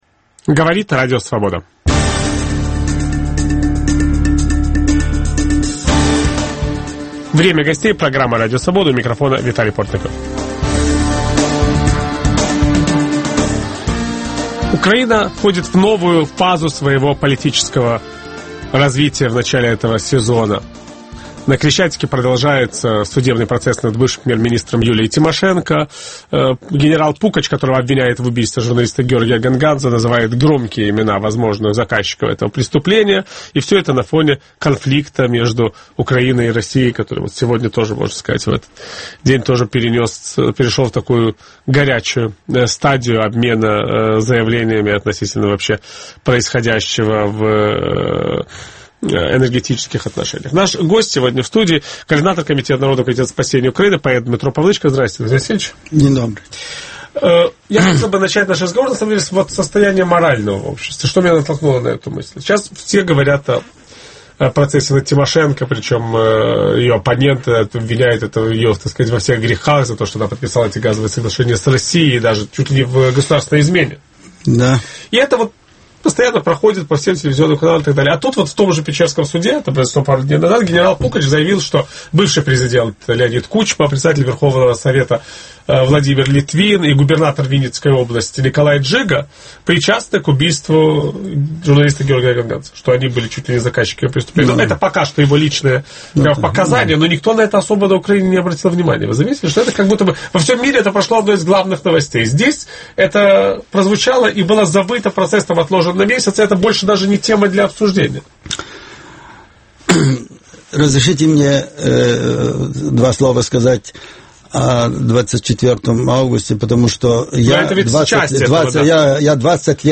Сможет ли Виктор Янукович сделать выбор между Россией и Европой? Как складываются отношения между новой украинской властью и зарубежными украинцами? Участвует координатор Комитета спасения Украины писатель Дмитро Павлычко.